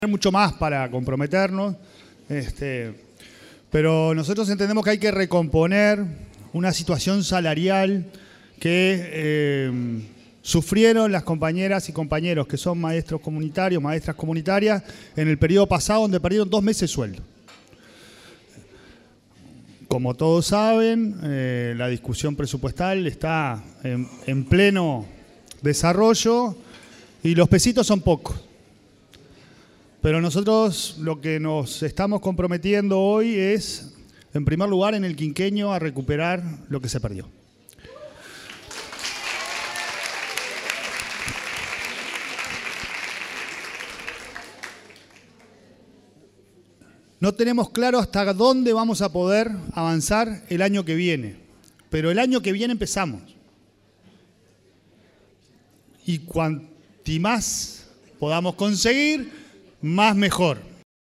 Palabras del presidente de la ANEP, Pablo Caggiani
Palabras del presidente de la ANEP, Pablo Caggiani 15/08/2025 Compartir Facebook X Copiar enlace WhatsApp LinkedIn El titular de la Administración Nacional de Educación Pública (ANEP), Pablo Caggiani, acompañó al presidente de la República, Yamandú Orsi, en el encuentro que mantuvo con un grupo de más de 500 maestras comunitarias de todo el país en la residencia de Suárez y Reyes e hizo uso de la palabra.